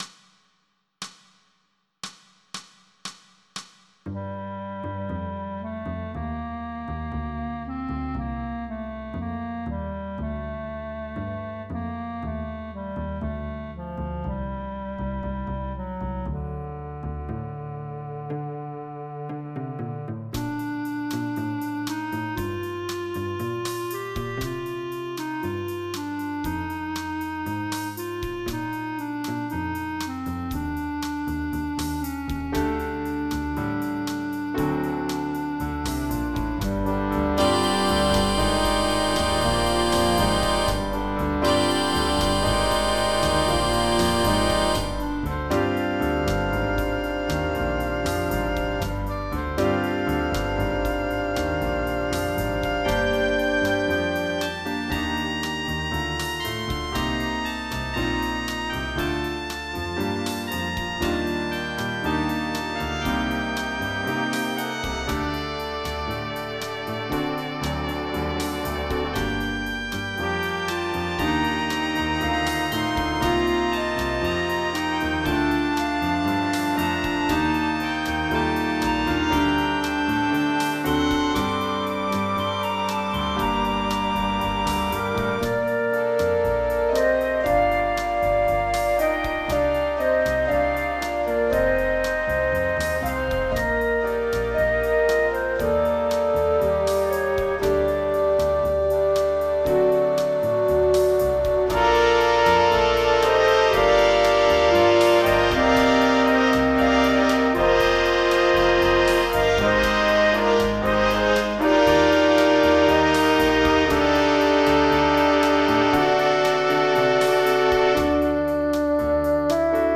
General MIDI